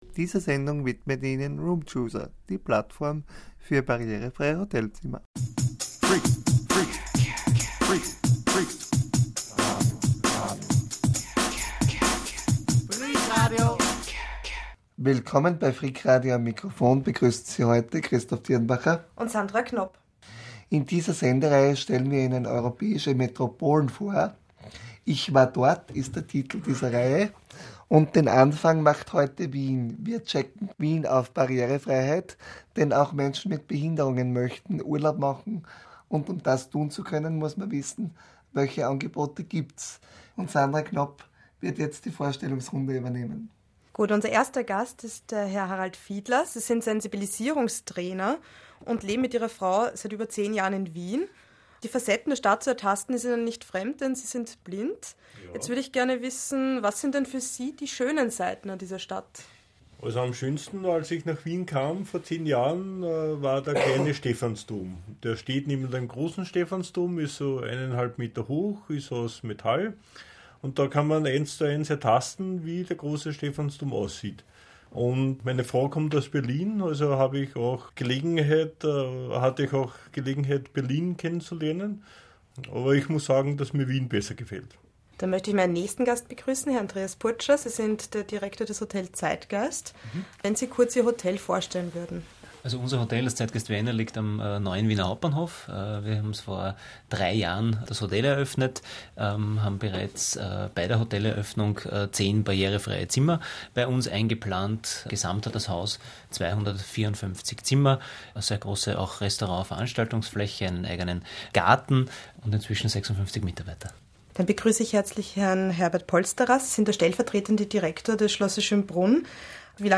Gesprächsrunde über die barrierefreien Facetten Wiens.